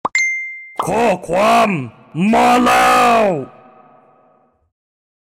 หมวดหมู่: เสียงเรียกเข้า
คำอธิบาย: ข้อความมาแล้ว, แบบดุดัน, มันจะแจ้งให้คุณทราบว่ามีข้อความใหม่.